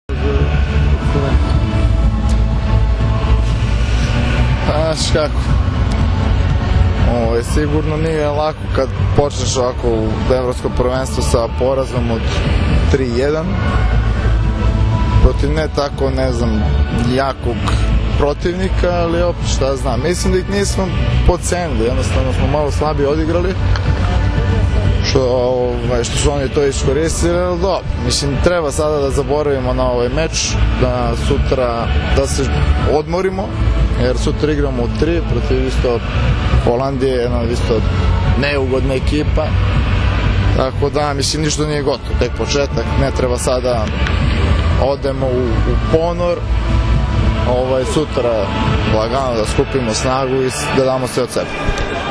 IZJAVA NIKOLE KOVAČEVIĆA